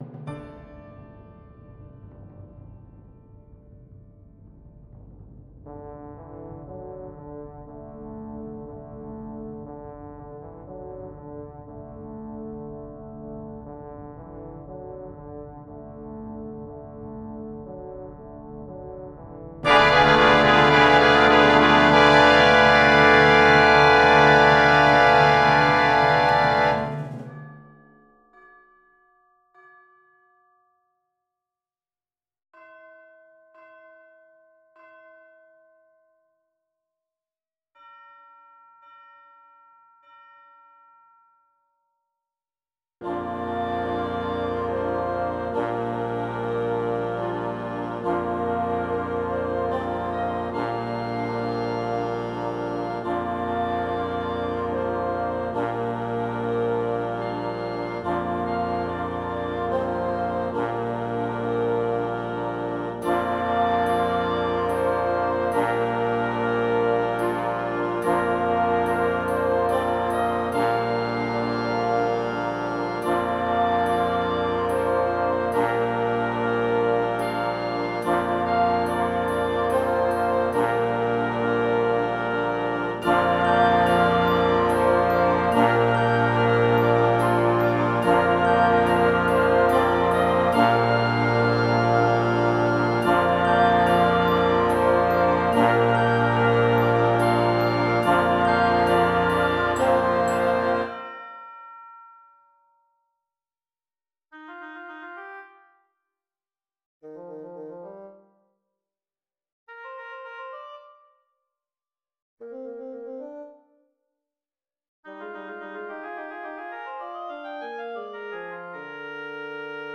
Gattung: Konzertwerk
C Besetzung: Blasorchester PDF
Komposition für großes Blasorchester